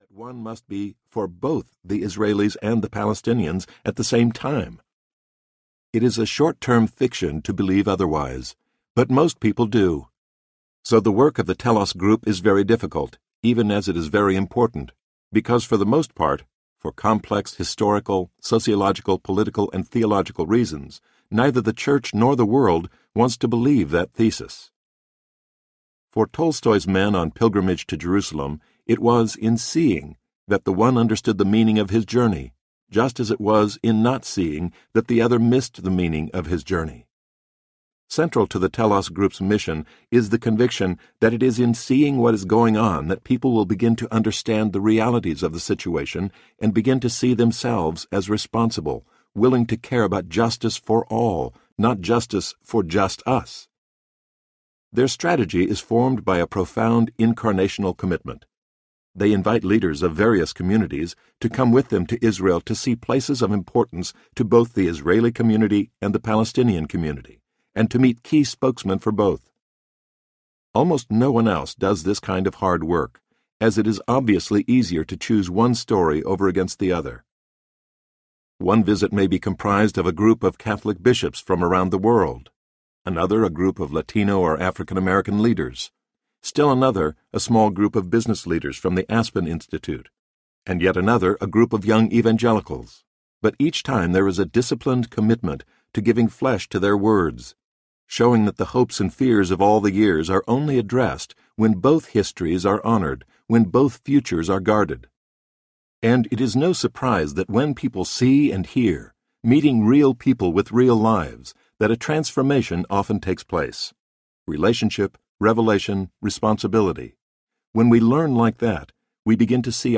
Visions of Vocation Audiobook
Narrator
8.0 Hrs. – Unabridged